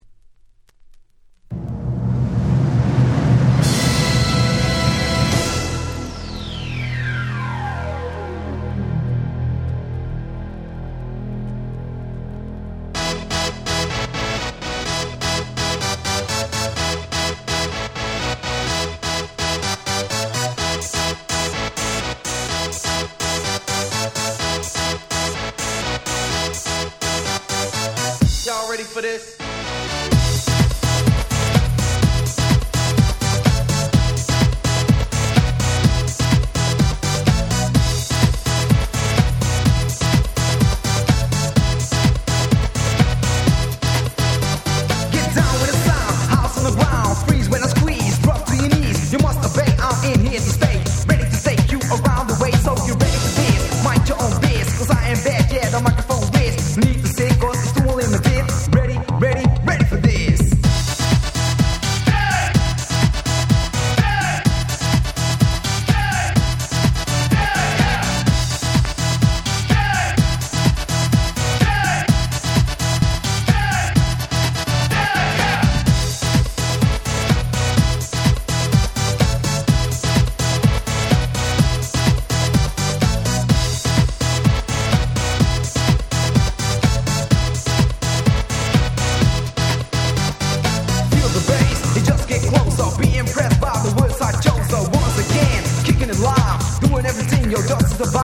ジュリアナクラシック！！
ド派手なシンセが印象的な